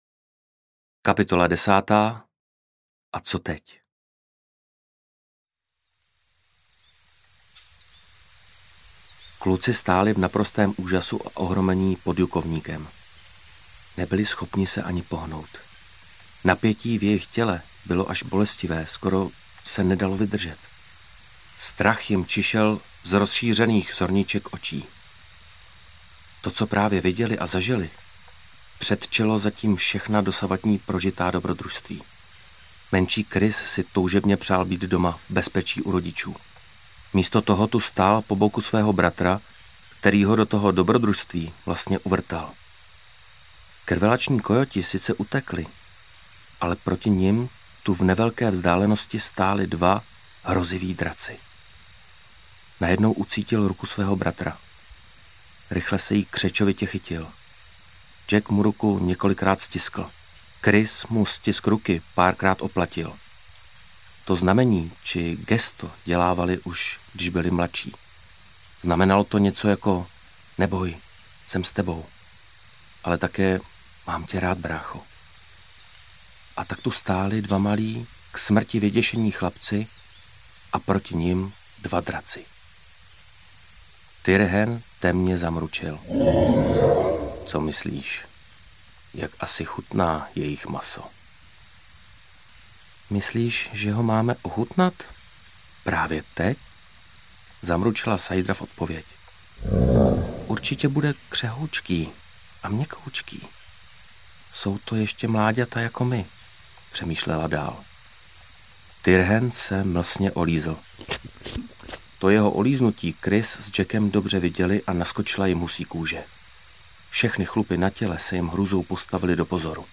Sajdra a Tyrhen objevují svět - verze se zvuky audiokniha
Ukázka z knihy
Posluchači uslyší zvuky draků, kojotů, medvědů, ptáků, cvrčků, smích dětí i rodičů, dovádění draků v oblacích a jejich smích, mávání dračích křídel, údery jejich ocasů, kroky draků a jejich mručení, zvuky aut i motorky, troubení parníků, šplouchání vody v jezeře, šplhání chlapců na strom a jejich zrychlený dech, bzučení včel, kojotí vytí, houkání sovy, zvuk kytary i foťáku, rádio, řinčení nádobí.
sajdra-a-tyrhen-objevuji-svet-verze-se-zvuky-audiokniha